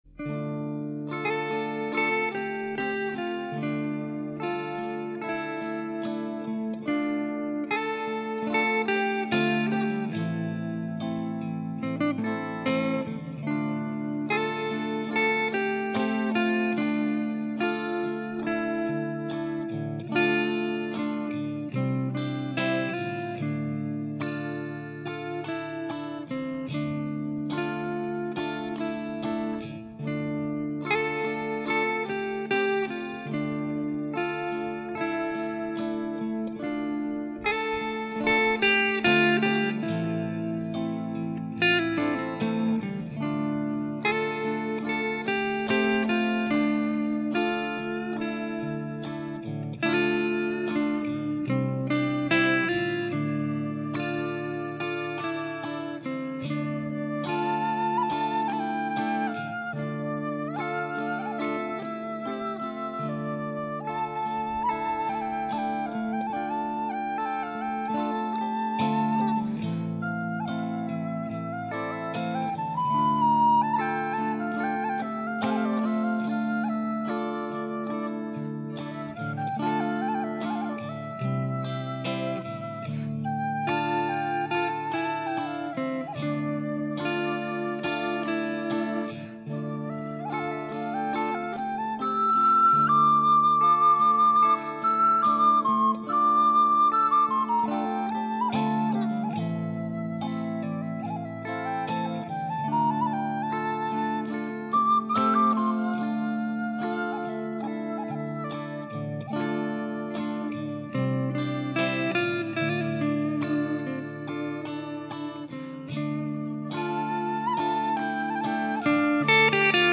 Etno-jazz chitarra elettrica, flauto diritto